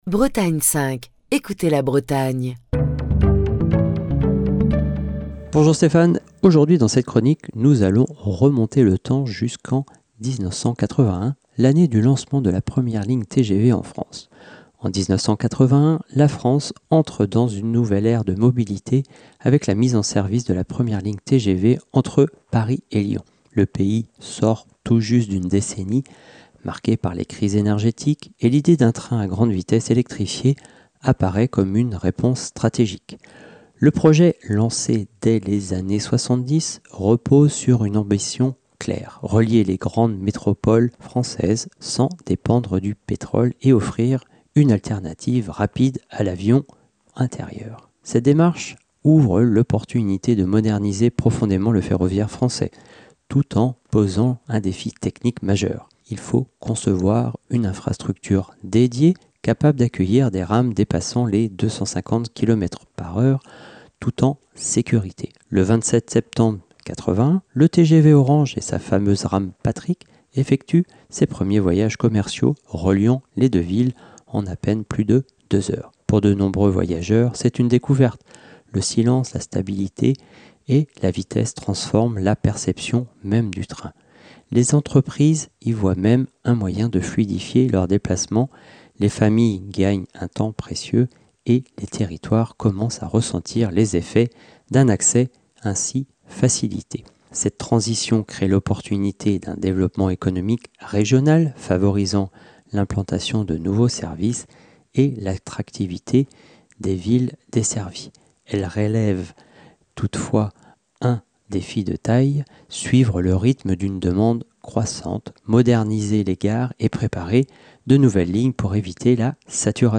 Chronique du 4 décembre 2025.